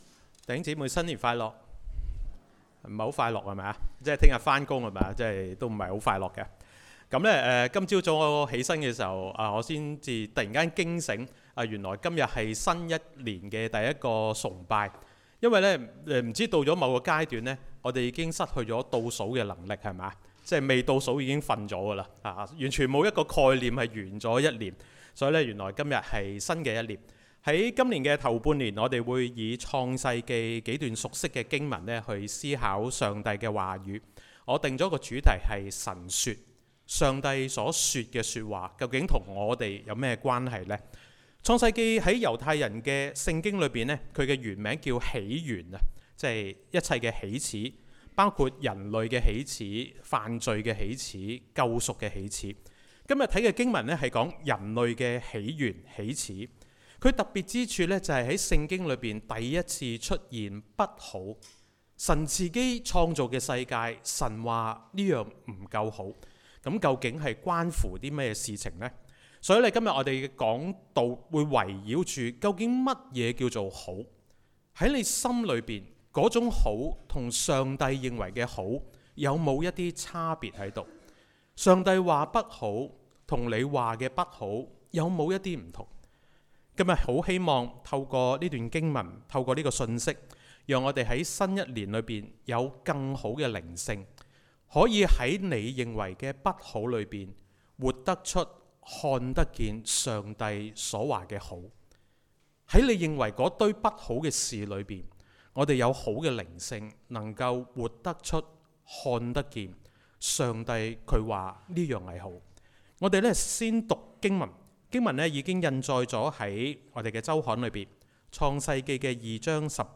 講道重溫
講道類別 : 主日崇拜 經文章節 : 創世記 2 : 18 - 22